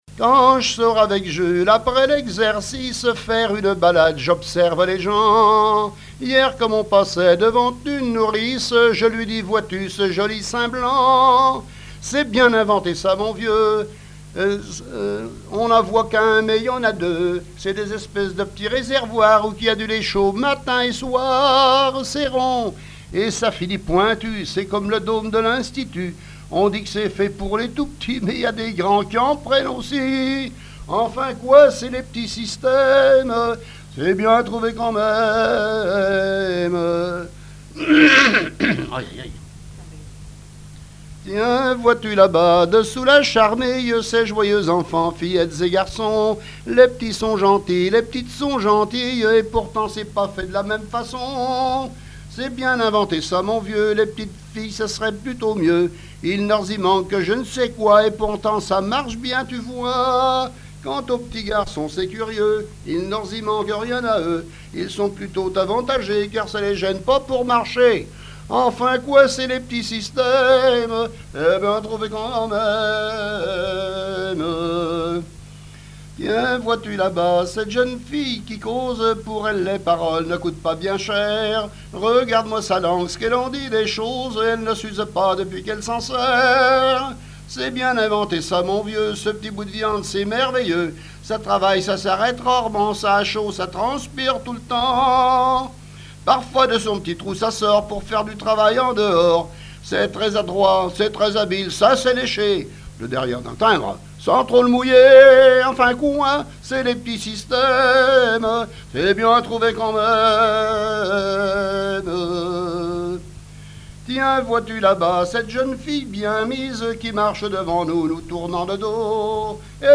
Mémoires et Patrimoines vivants - RaddO est une base de données d'archives iconographiques et sonores.
Genre strophique
Catégorie Pièce musicale inédite